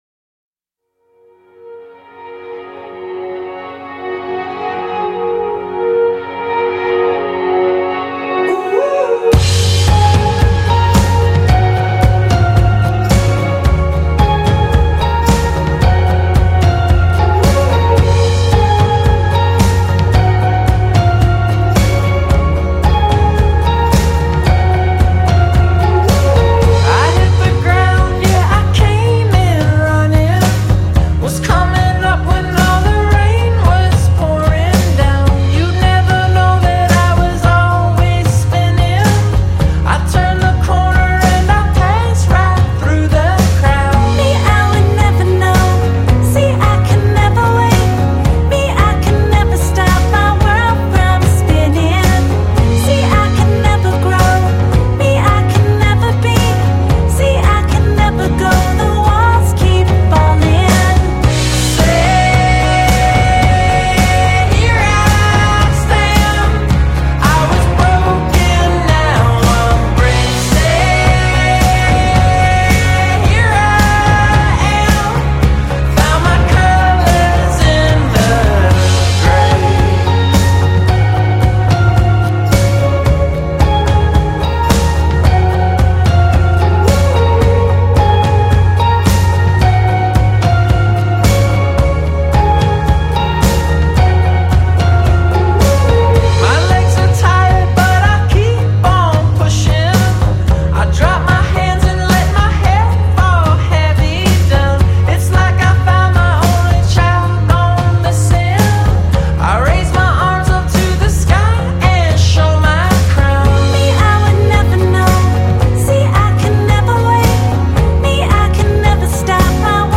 本期音乐为美国独立音乐专题。